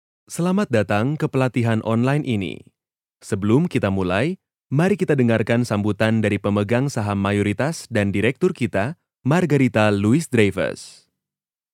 His voice has warm, clear and fresh tone.
Sprechprobe: eLearning (Muttersprache):
Indonesia voice over artist with warm, clear tone